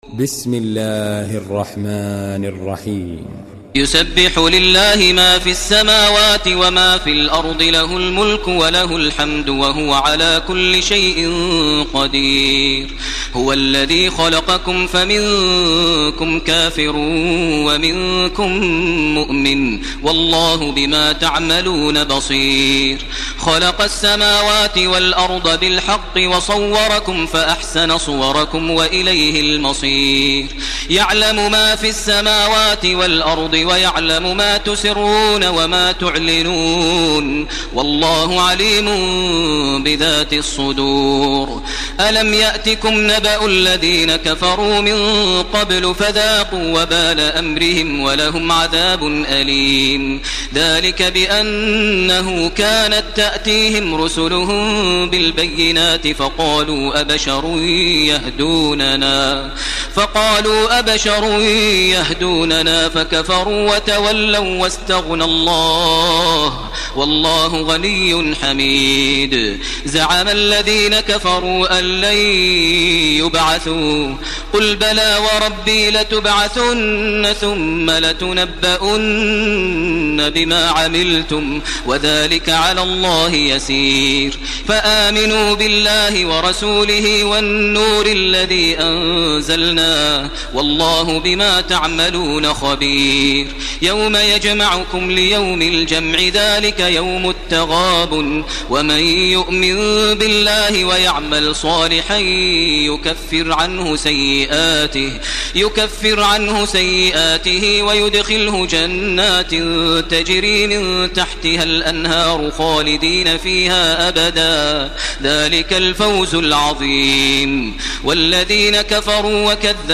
Surah আত-তাগাবুন MP3 by Makkah Taraweeh 1431 in Hafs An Asim narration.
Murattal Hafs An Asim